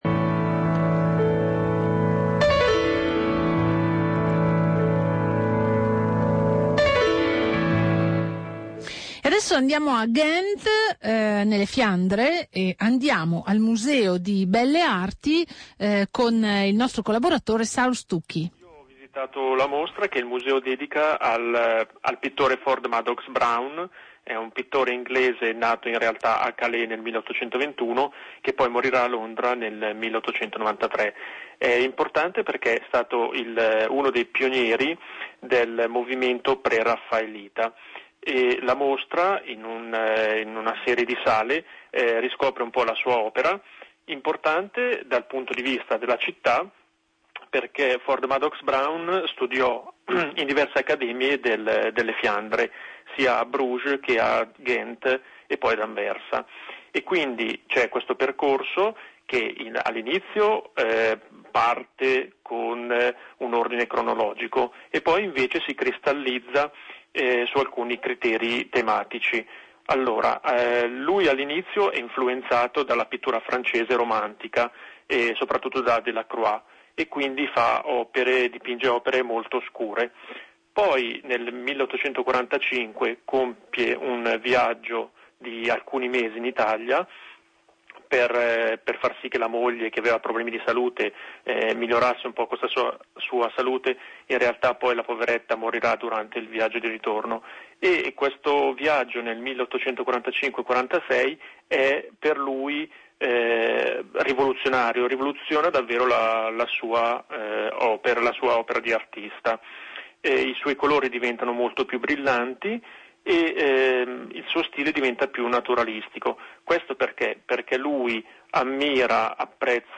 alla trasmissione di Radio Popolare I girasoli, condotta in studio